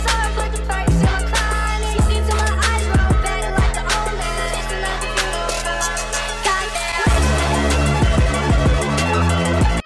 Double drinking tropical drink emoji sound effects free download
Double drinking tropical drink emoji Mp3 Sound Effect